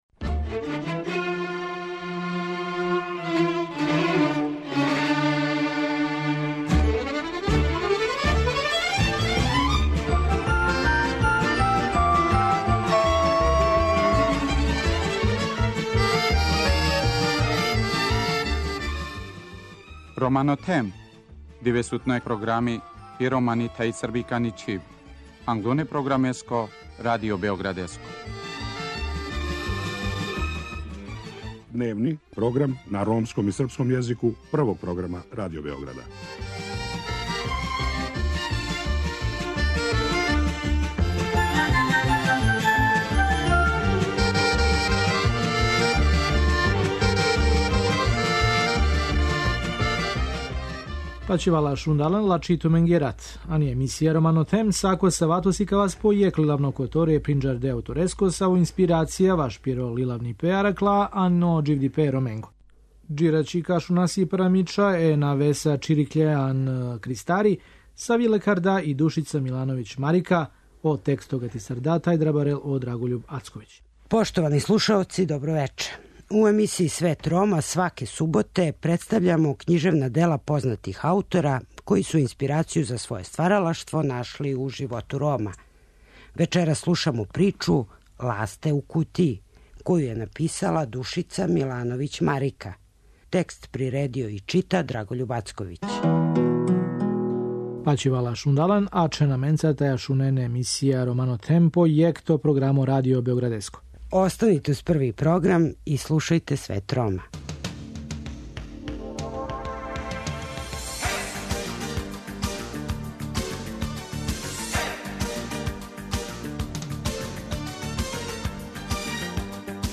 У емисији Свет Рома, сваке суботе емитујемо књижевна дела познатих аутора, који су инспирацију за своје стваралаштво нашли у животу Рома.